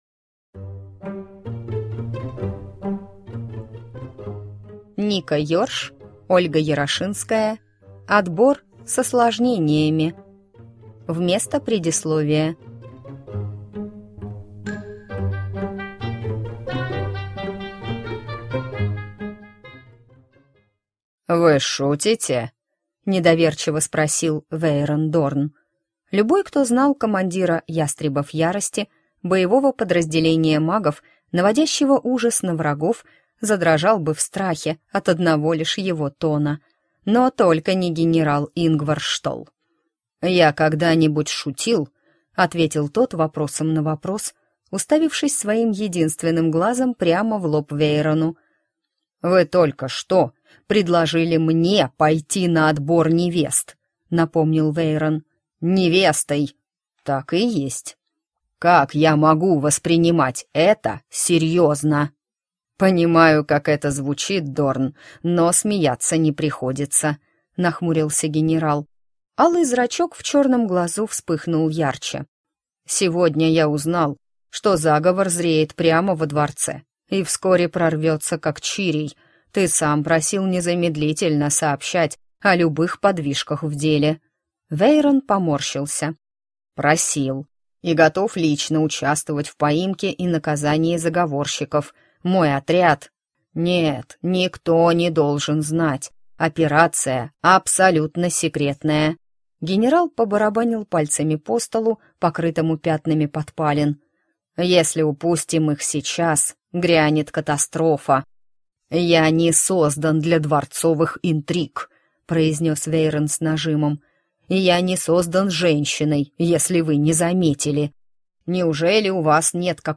Аудиокнига Отбор с осложнениями | Библиотека аудиокниг
Прослушать и бесплатно скачать фрагмент аудиокниги